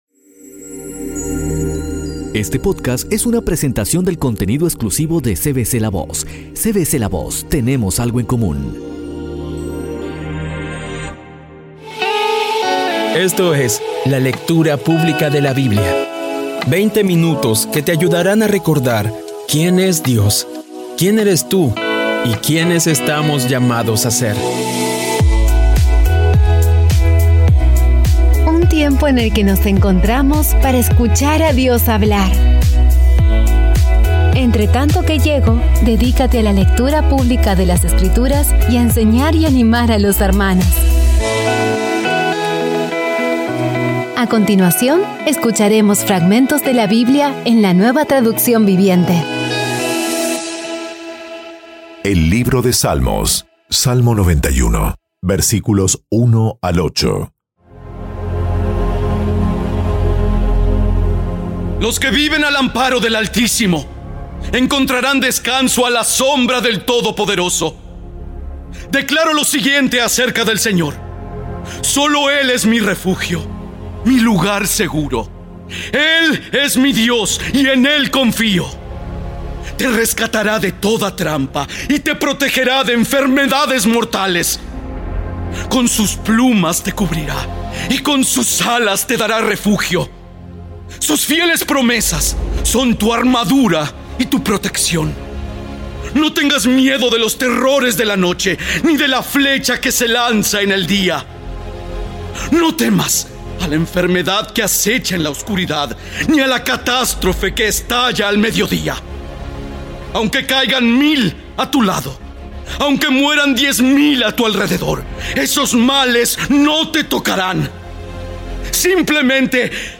Audio Biblia Dramatizada Episodio 223
Poco a poco y con las maravillosas voces actuadas de los protagonistas vas degustando las palabras de esa guía que Dios nos dio.